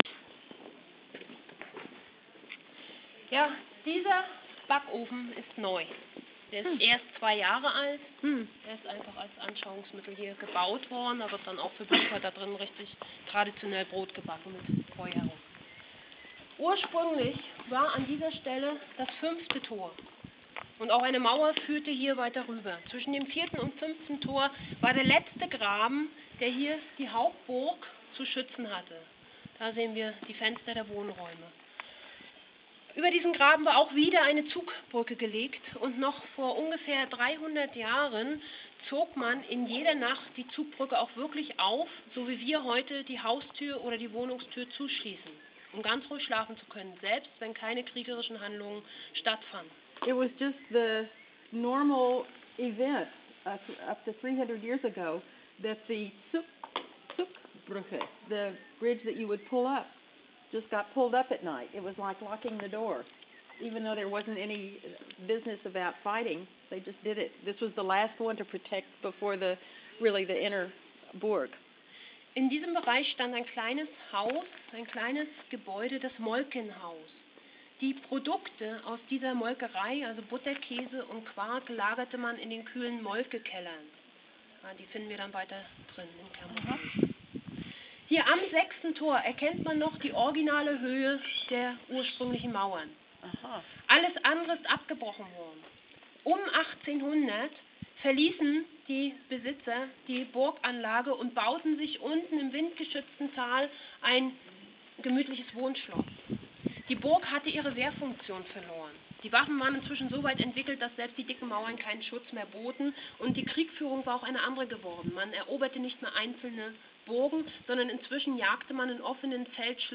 Führung durch den Burg / Tour Through the Castle